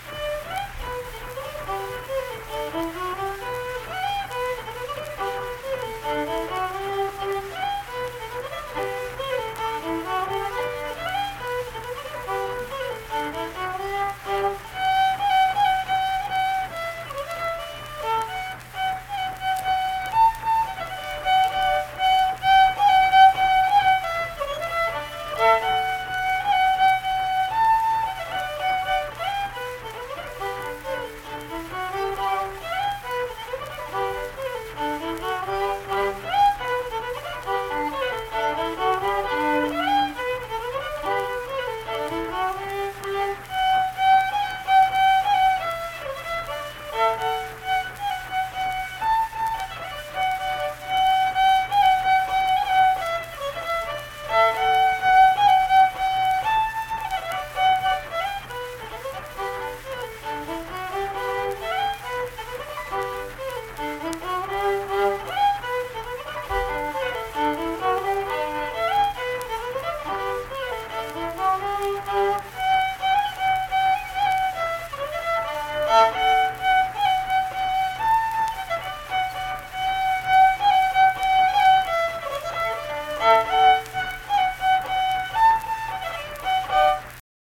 Unaccompanied fiddle music performance
Instrumental Music
Fiddle